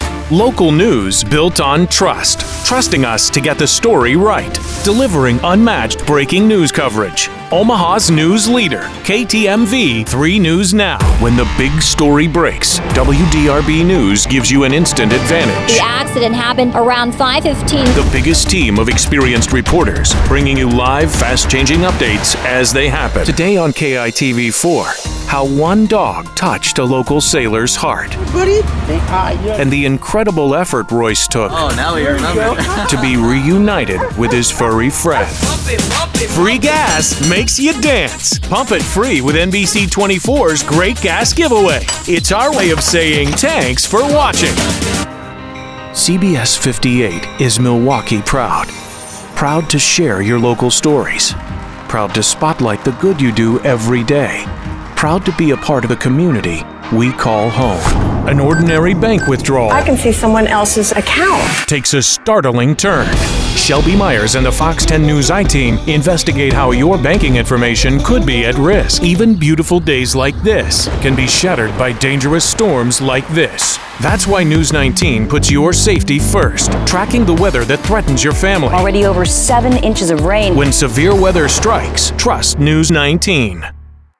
Conversational, Honest, Caring, Sincere
TV and Radio, Promo